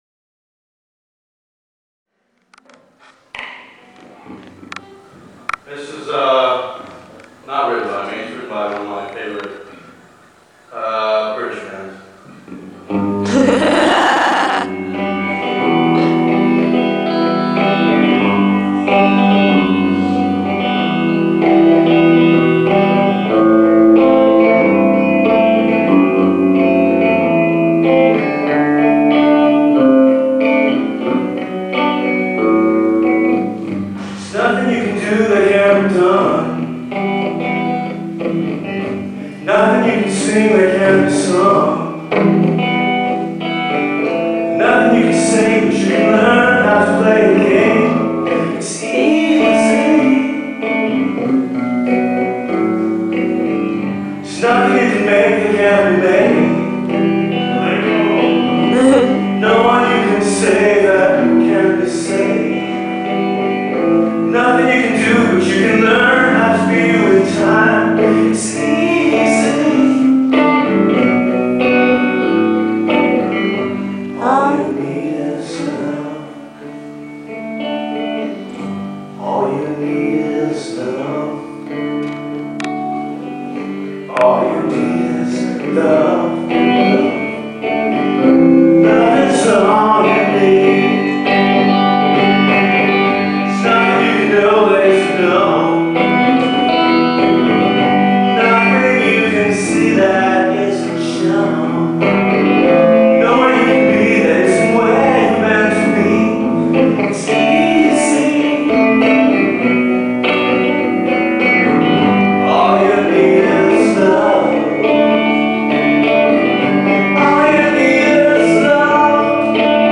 live in concert
Jan Popper Theater, UCLA, 2005